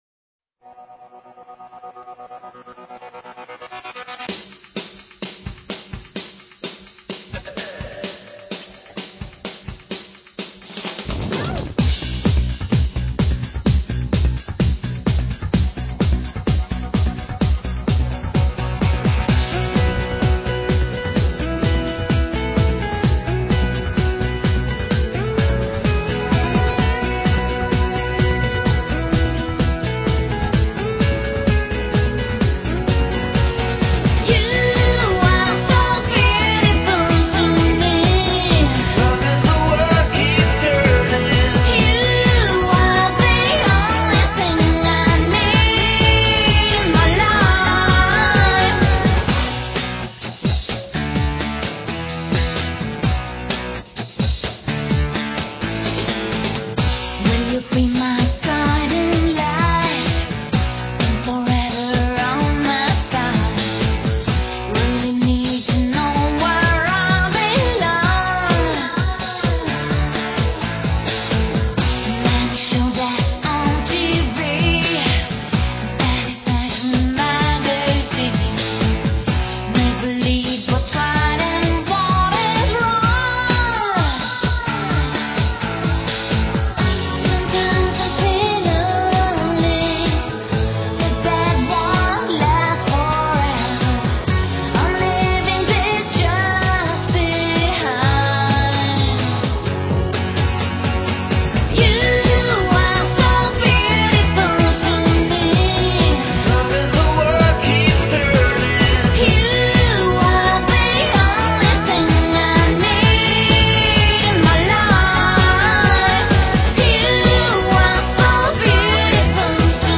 Жанры: синти-поп, танцевальная музыка,
евродиско, поп-рок, евродэнс